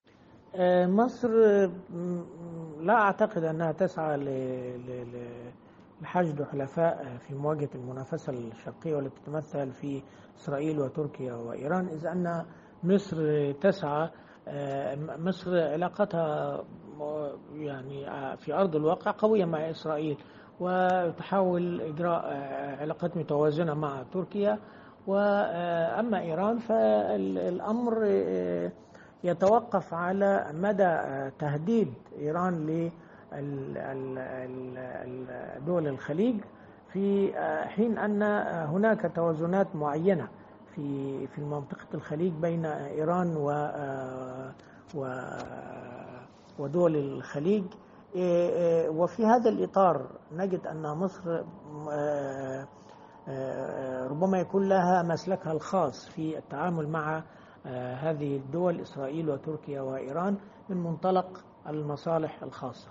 الكاتب الصحفي والمحلل السياسي